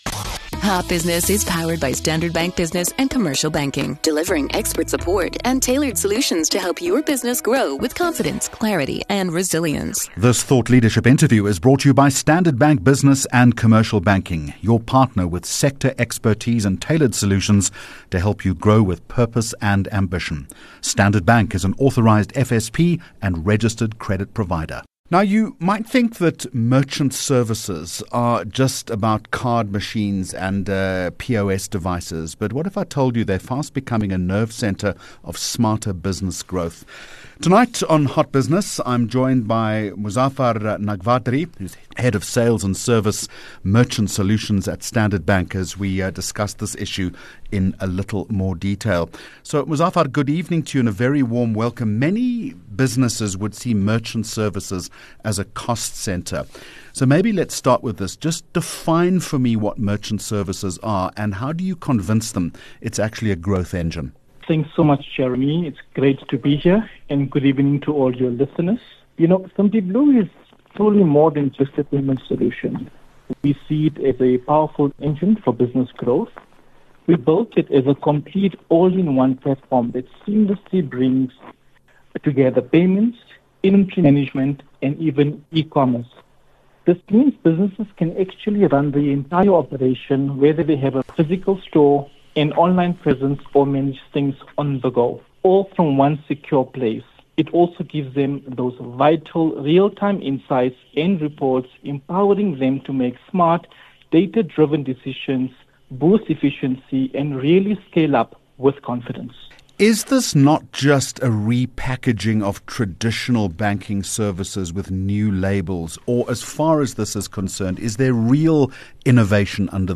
24 Jun Hot Business Interview